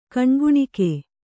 ♪ kaṇguṇike